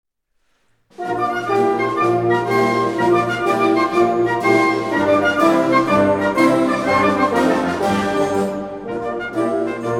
Categorie Harmonie/Fanfare/Brass-orkest
Subcategorie Hedendaagse blaasmuziek (1945-heden)
Bezetting Ha (harmonieorkest)
4 perc (Trng, tamb mil, ptto sosp, gr c)